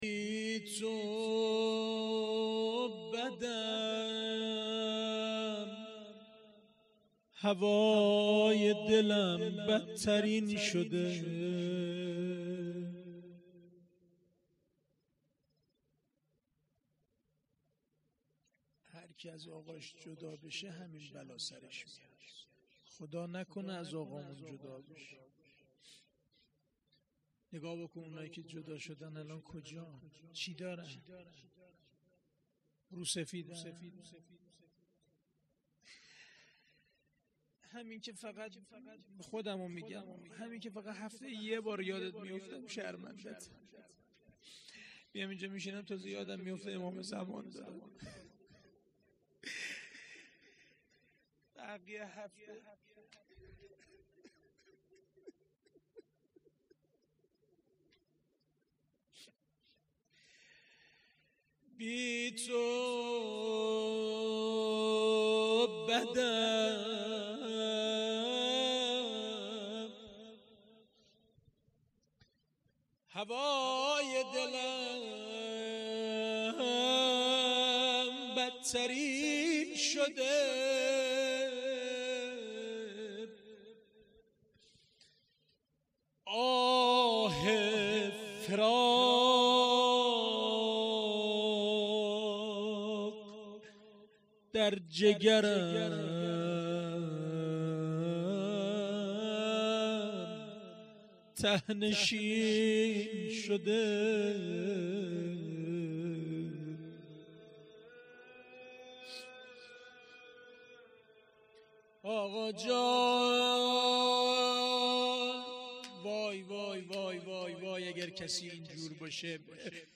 جلسه هفتگی
روضه-حضرت-زهرا-س.mp3